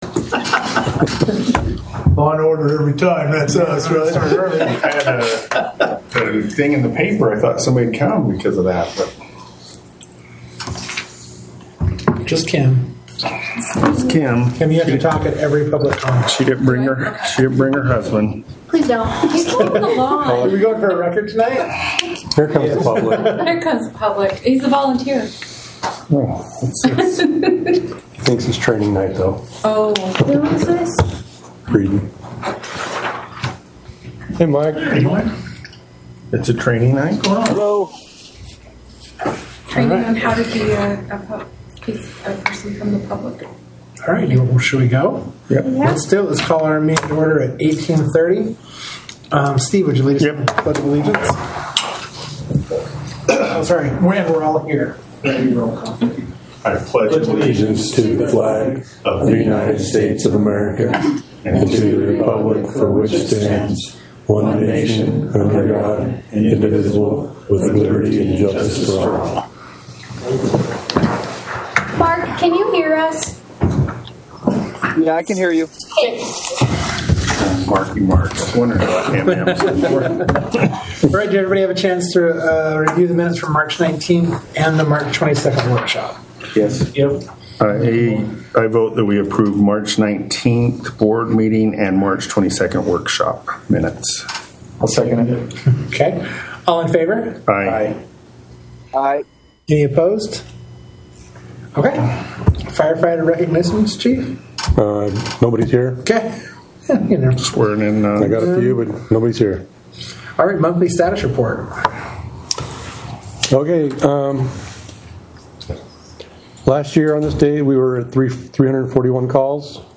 Board Meeting
Notice is hereby given that the North Tooele Fire Protection Service District will hold a board meeting on April 16, 2025, at 6:30 p.m. at the Stansbury Park Fire Station, 179 Country Club, Stansbury Park, UT.